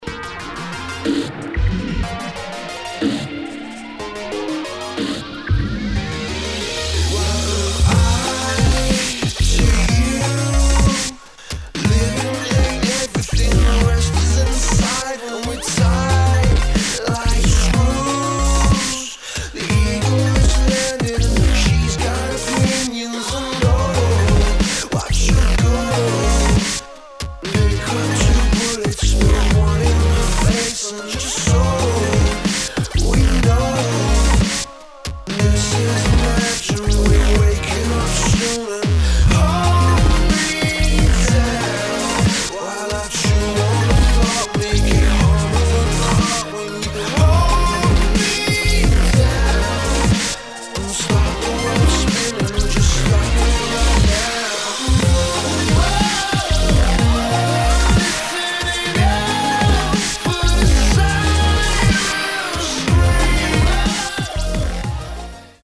[ DUBSTEP / TRAP / GRIME ]